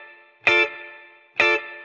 DD_TeleChop_130-Dmin.wav